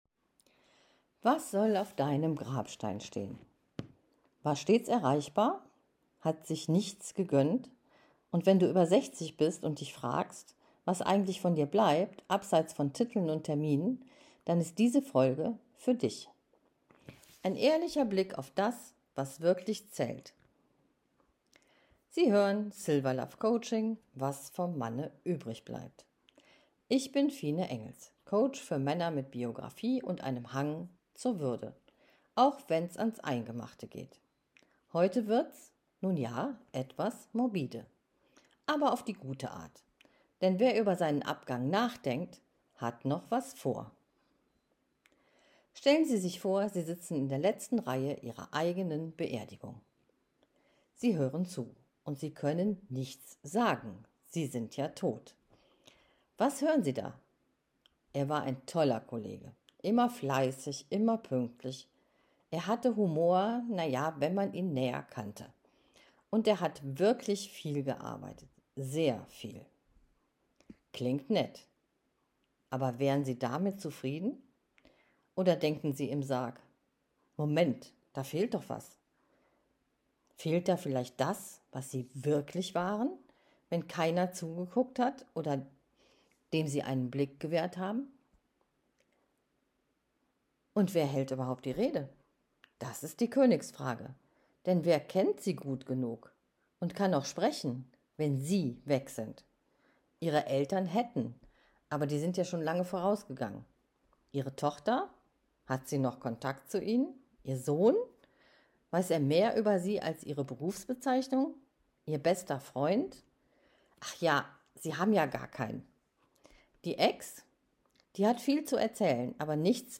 Ein Gespräch über Männlichkeit jenseits von Titeln,